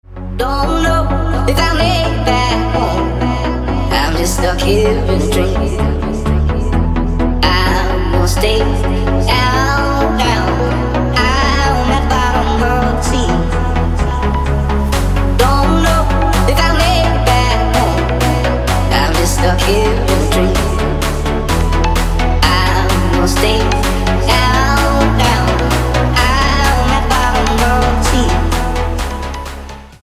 • Качество: 256, Stereo
поп
спокойные
Original Mix.